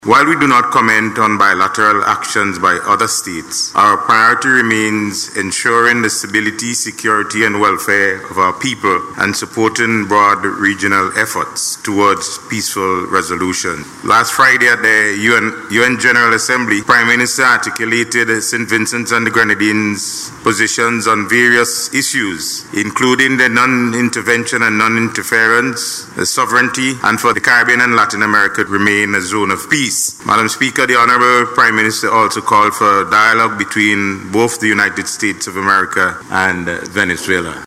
Speaking in Parliament yesterday, Minister of Foreign Affairs, Foreign Trade and Consumer Affairs, Hon. Frederick Stephenson, noted that while Saint Vincent and the Grenadines does not publicly comment on the bilateral actions of other states, the government’s unwavering focus remains on protecting the interests of its people.